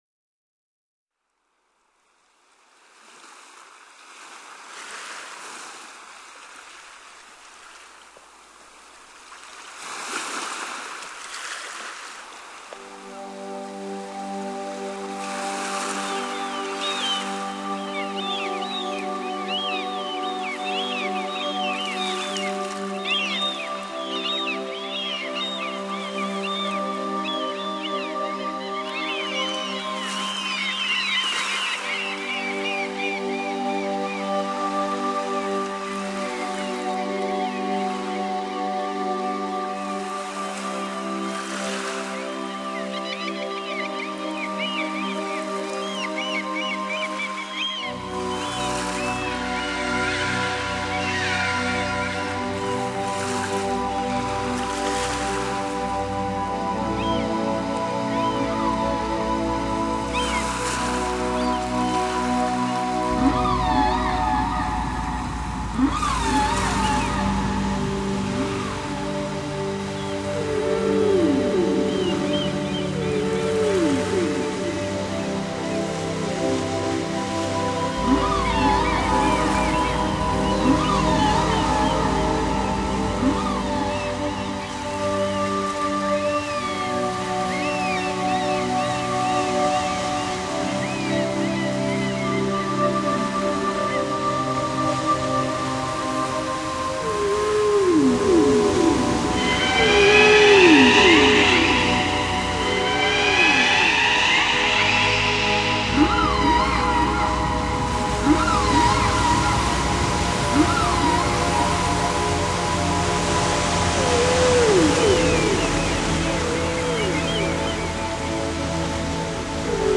3D spatial surround sound "Ocean waves"
3D Spatial Sounds